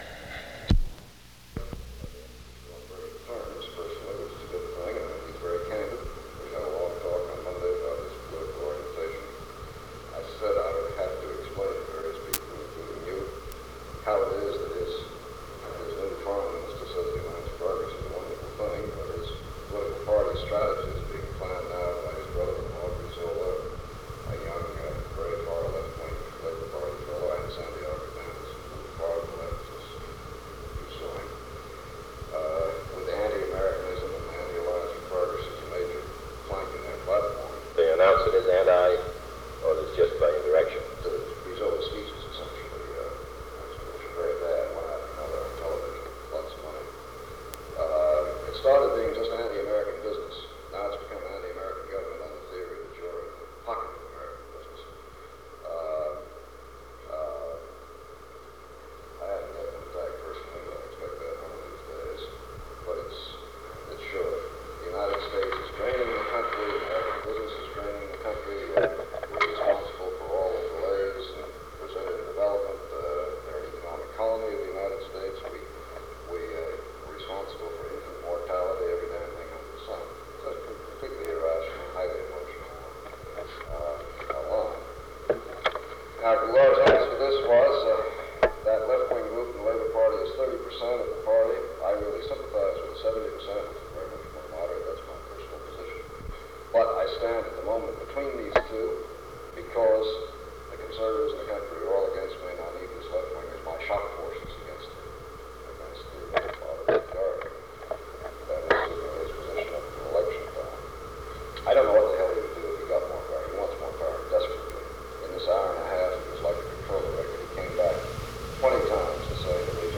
Meeting on Brazil
Secret White House Tapes | John F. Kennedy Presidency Meeting on Brazil Rewind 10 seconds Play/Pause Fast-forward 10 seconds 0:00 Download audio Previous Meetings: Tape 121/A57.